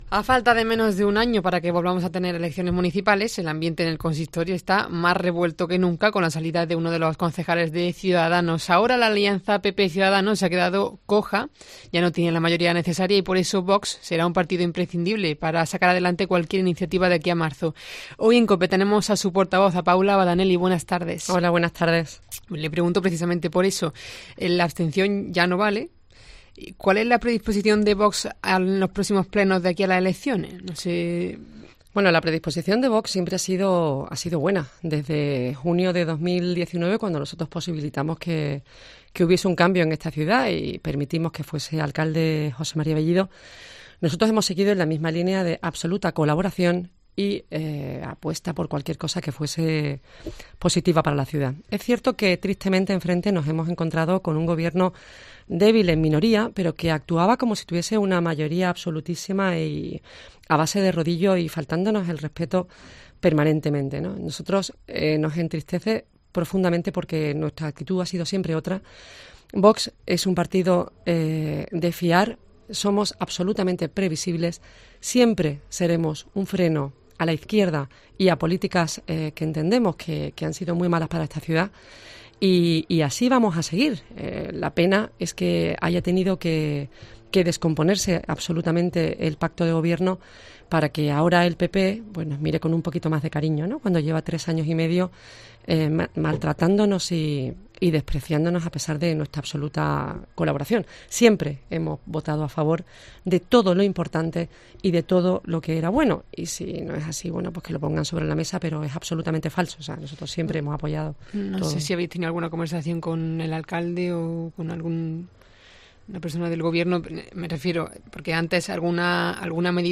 La portavoz de Vox en el Ayuntamiento de Córdoba ha pasado por los micrófonos de COPE tras la salida de su partido del IMTUR
Escucha la entrevista a Paula Badanelli (Vox)